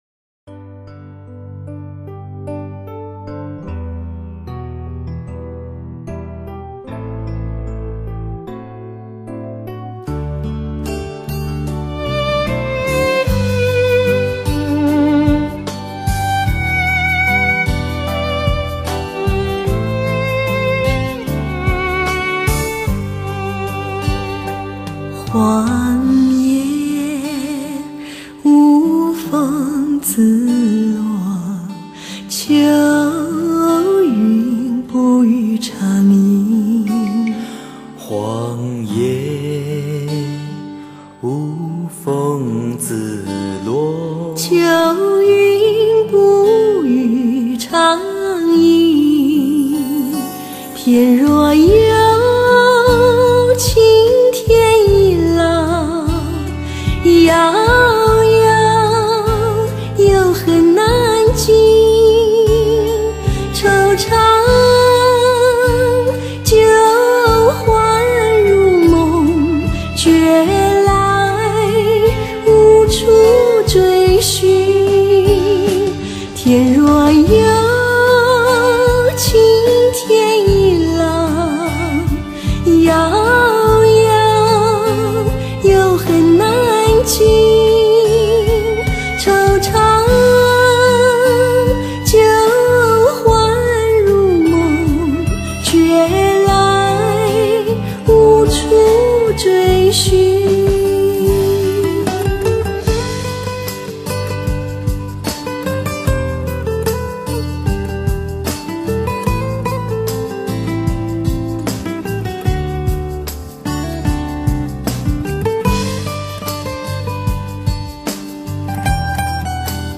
专辑风格：发烧